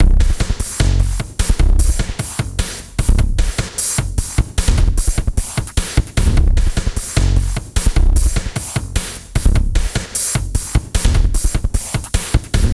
B + D LOOP 3 2.wav